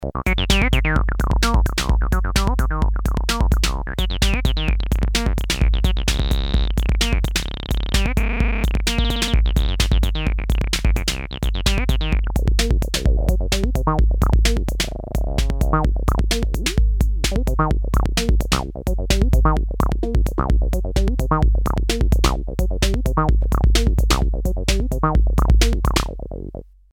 Tb-3 generates a monophonic voice and 4 kind of digital oscillators models: saw and square (modeled on tb303), leads, FX and bass.
jam with drum TR-808